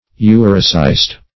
Urocyst \U"ro*cyst\, n.